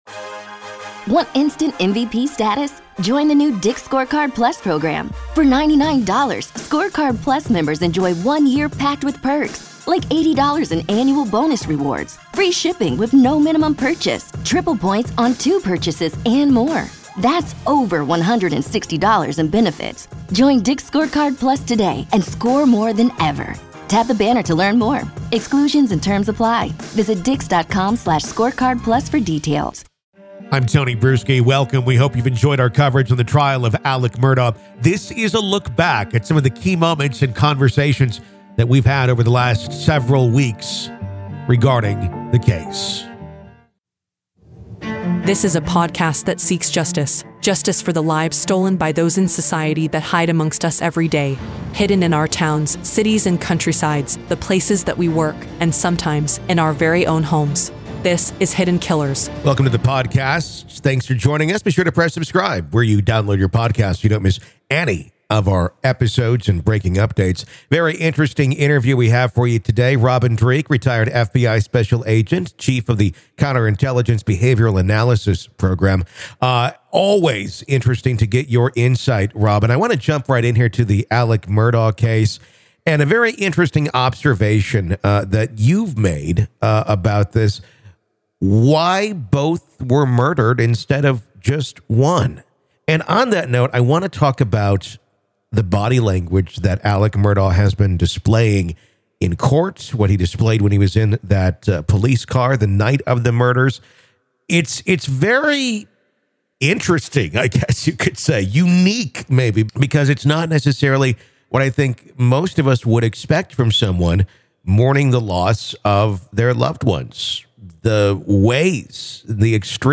This is a retrospective look at the Alex Murdaugh case, as well as a riveting discussion that we've had with industry professionals over the course of the past few weeks.